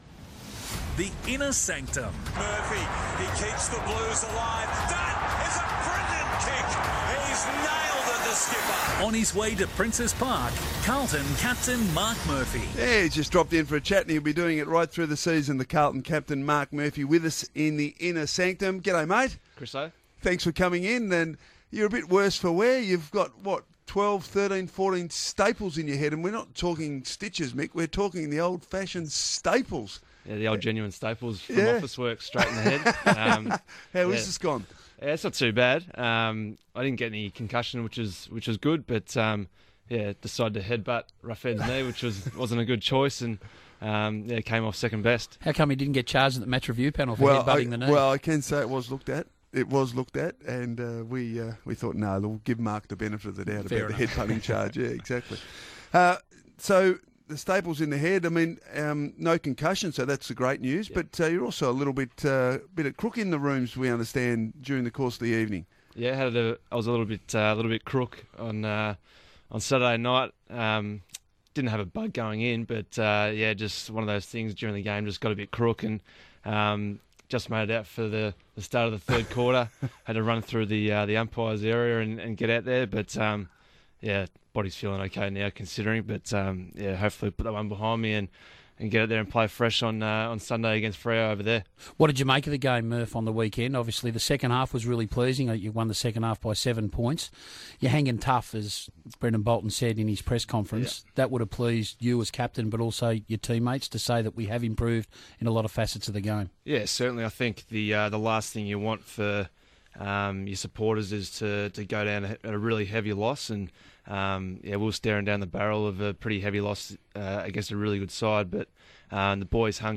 Carlton captain Marc Murphy talks on Radio Sport National's new program 'The Inner Sanctum'.